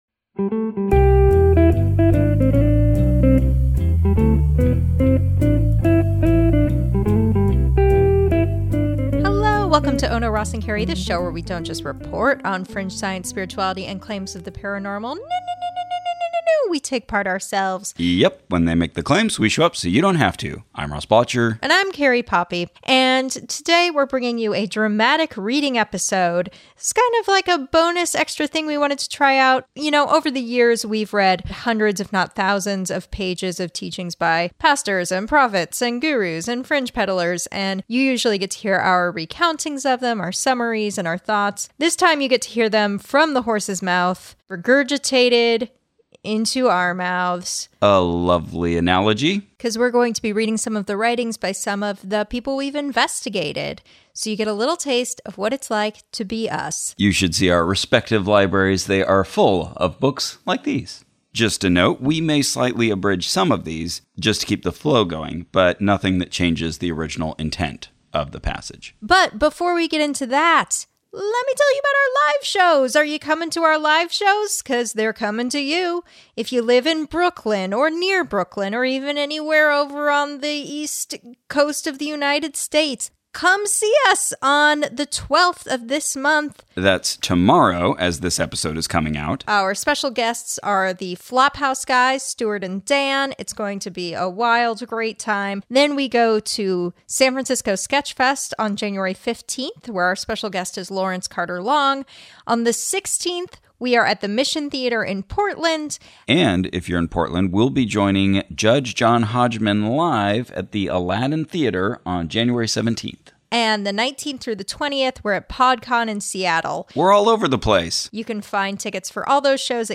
Dramatic Performance Edition
Hear from Rael, Tony Alamo, Bob Larson, and LRH in this dramatic reading edition.